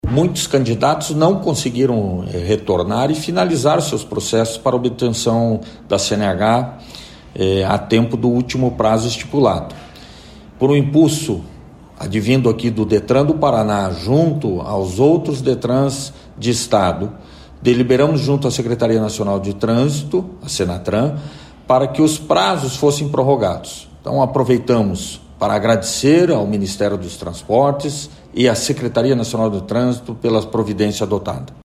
Sonora do diretor-presidente do Detran do Paraná, Adriano Furtado, sobre a prorrogação do prazo para obtenção da CNH de quem teria o processo vencendo no próximo dia 31